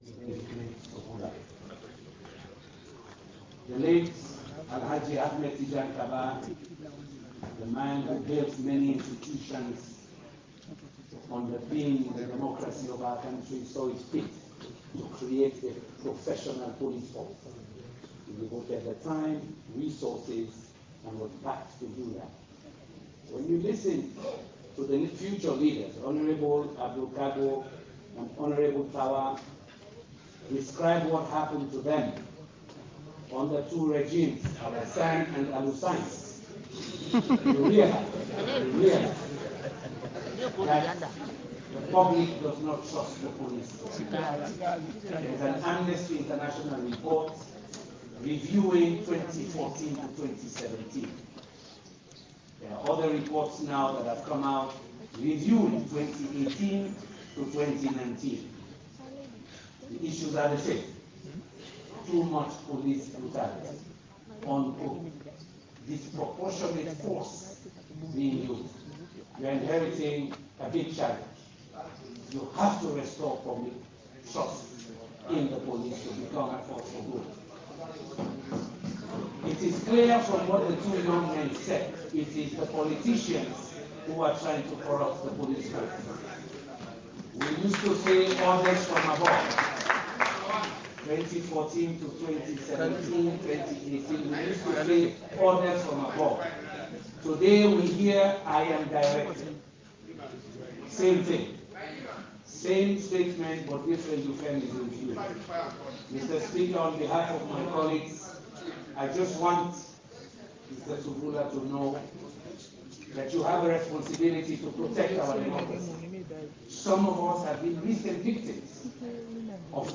Addressing the new IG in parliament, the NGC leader – Dr Kandeh Yumkella MP called on him to change the police force.
New-IG-approved-by-parliament-–-Dr-Kandeh-Yumkella-speaks.mp3